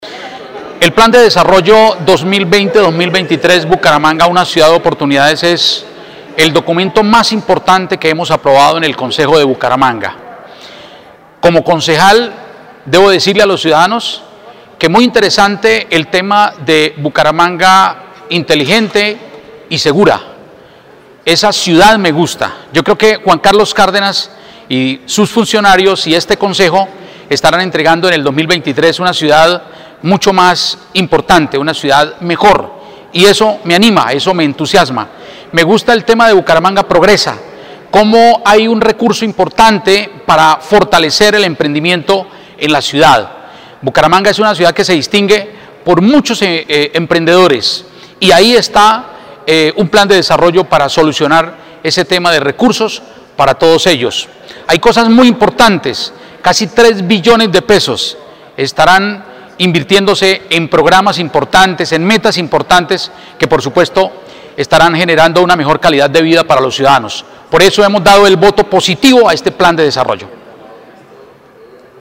Luis Fernando Castañeda Pradilla, presidente de la Comisión de Hacienda
Descargar audio: Concejal Luis Fernando Castañeda Pradilla, presidente de Comisión